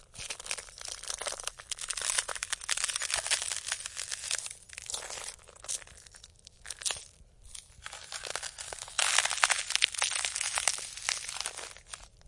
冬天 " 冰裂缝 薄脆的
描述：冰裂缝薄脆。弗拉克
Tag: 裂纹 脆性